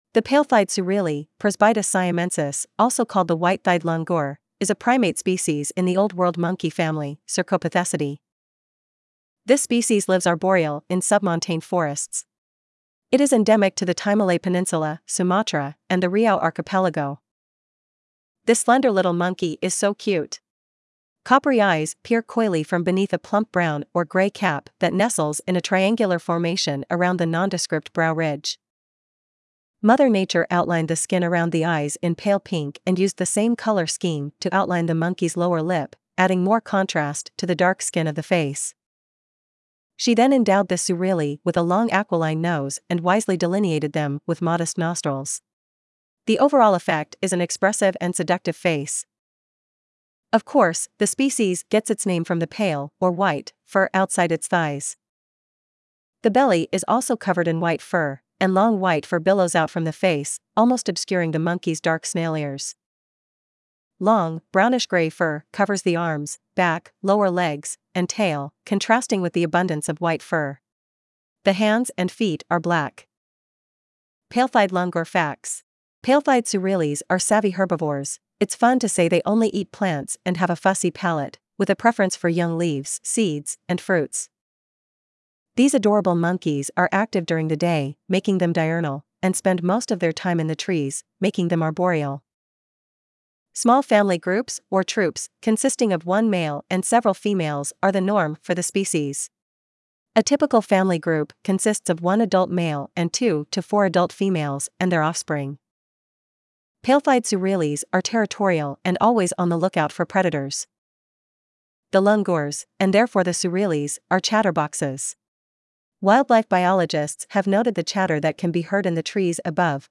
Wildlife biologists have noted the “chatter” that can be heard in the trees above when these primates converse with each other.
pale-thighed-langur.mp3